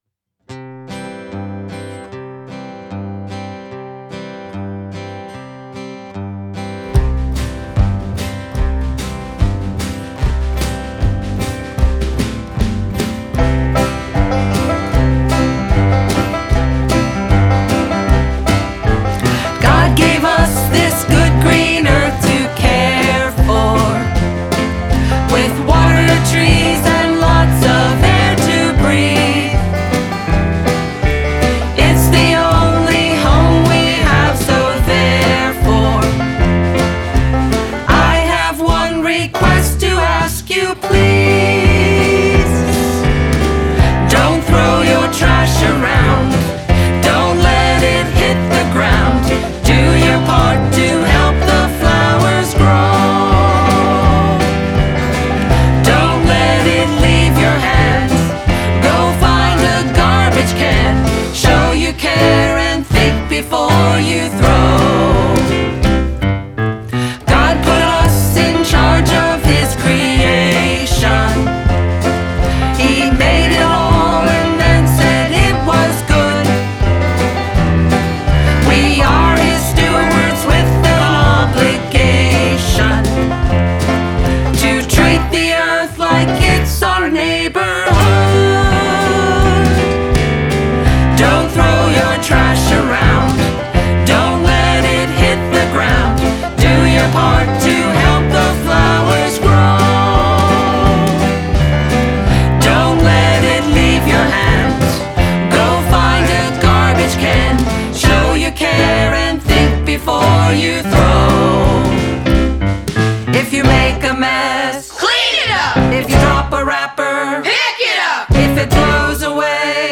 vocals, guitar, banjo, harmonica
upright bass
percussion
piano, trumpet, flugelhorn